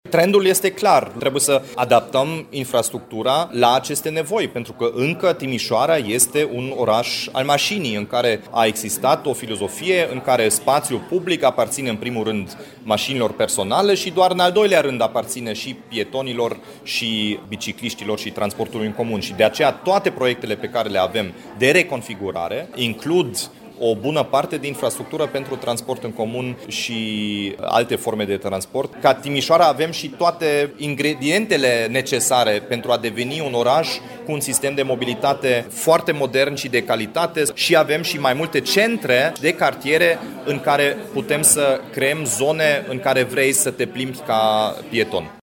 Declarația aparține primarului Dominic Fritz, ca urmare a celor mai recente date oferite de barometrul calității vieții în Timișoara.